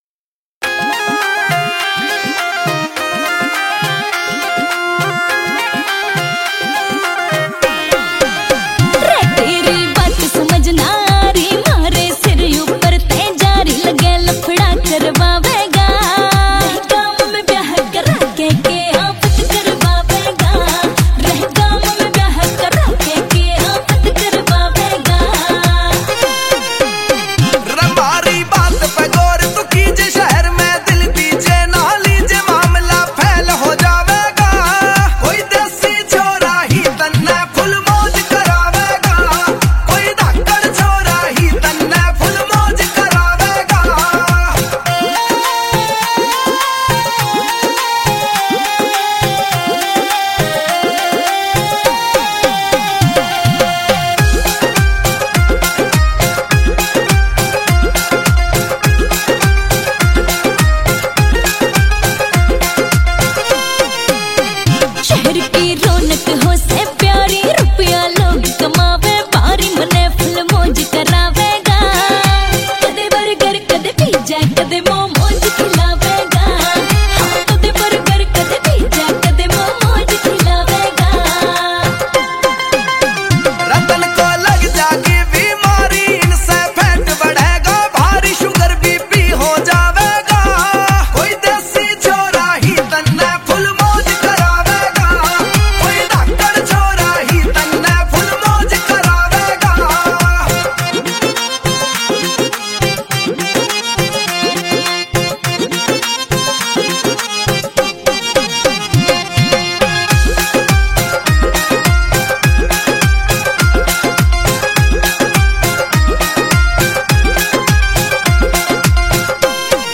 Category New Haryanvi Song 2023 Singer(s